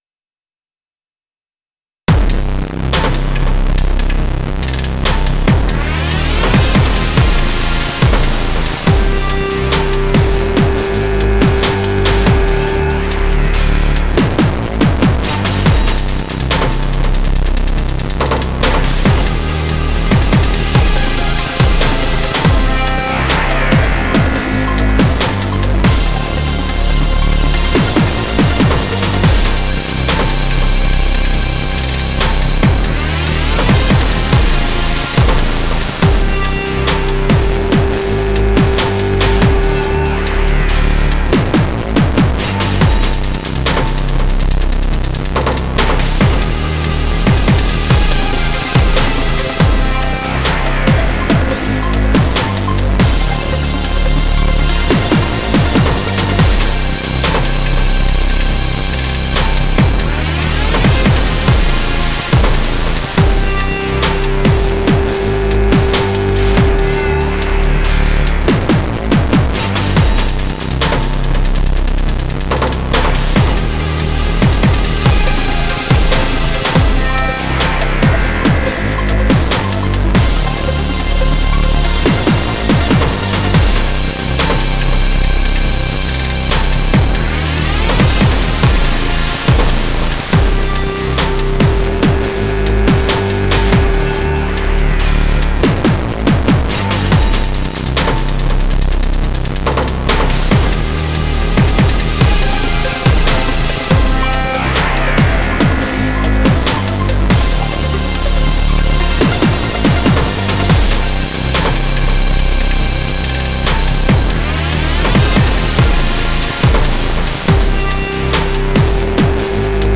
Can’t really convey it via streamed MP3 but the sound quality of this unit is unreal. It’s so hifi and detailed.